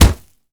punch_grit_wet_impact_09.wav